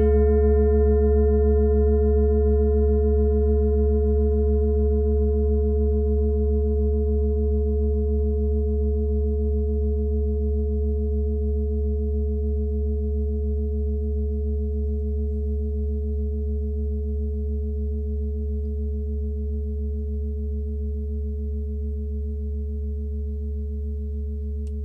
Klangschale Bengalen Nr.38
(Ermittelt mit dem Filzklöppel)